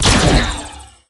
shotgun01.ogg